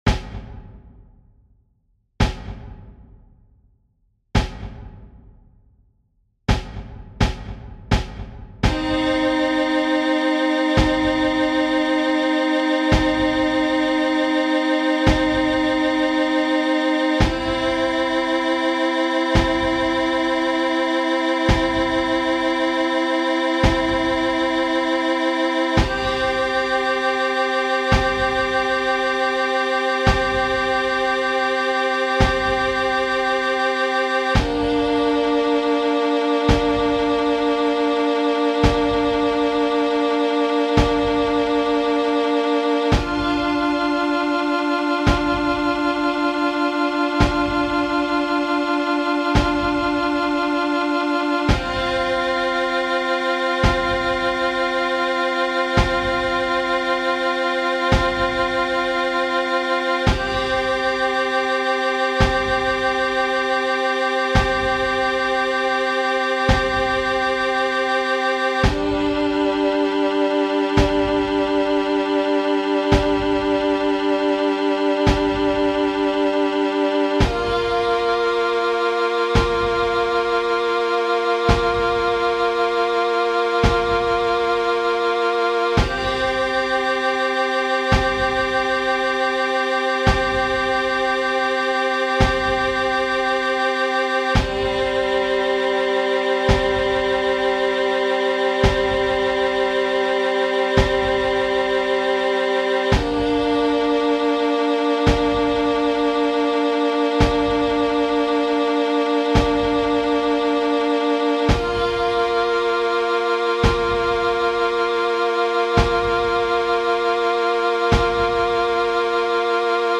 It has a lead-in, but it does NOT have a countdown.